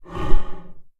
MONSTER_Breath_01_mono.wav